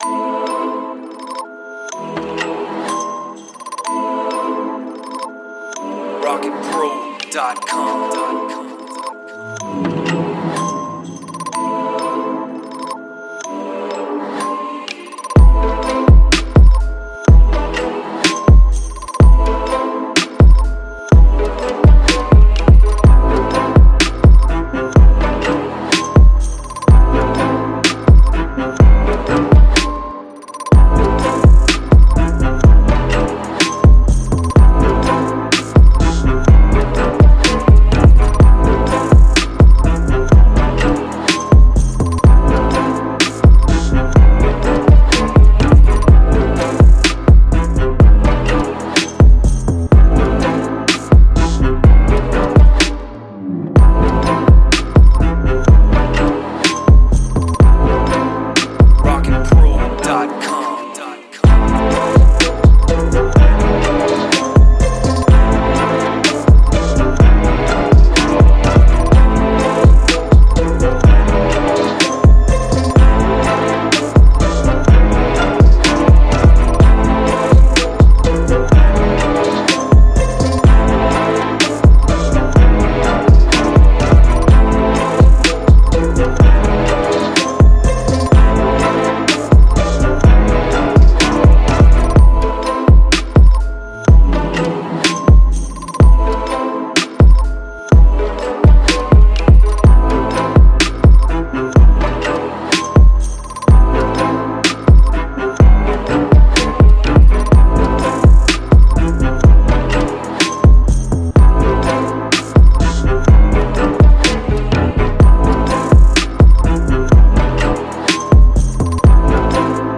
Story telling type beat with pianos, strings, and guitar.
93 BPM.